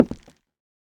Minecraft Version Minecraft Version latest Latest Release | Latest Snapshot latest / assets / minecraft / sounds / block / nether_wood / step1.ogg Compare With Compare With Latest Release | Latest Snapshot
step1.ogg